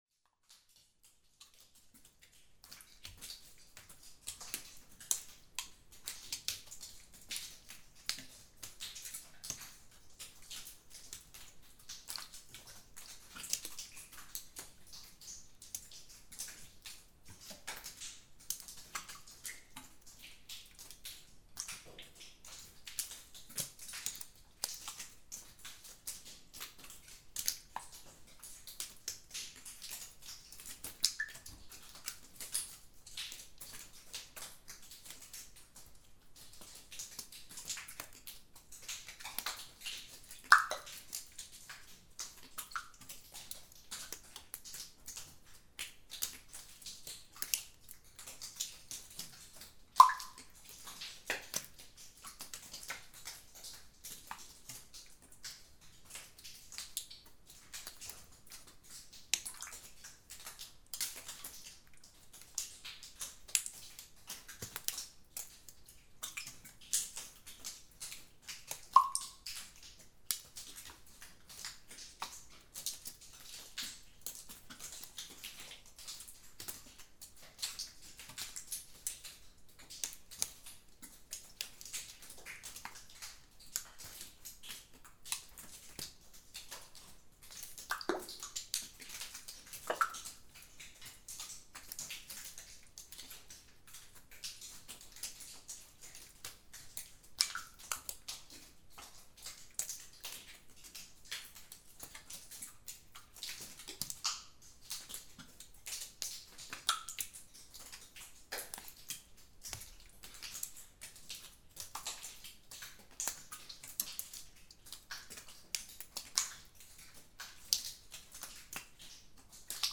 Sound in Víðgelmir Cave
Posted in Náttúra, tagged Cave, Dropar, Fljótstunga, Hellir, Hvítársíða, Rode NT1a, Sound Devices 788, Vatn, Viðgelmir on 20.7.2012| 10 Comments »
Many other lava tube caves have been discovered in Hallmundarhraun (formed around 900 AD), most notable Surtshellir and Stefánshellir .(*) This recording was made 30th of June 2012, close to the entrance, where ice from last winter was still melting. Water drops from the cave roof are falling into differenet places on the floor, in holes in the ice and on stones on rough surfaced floor.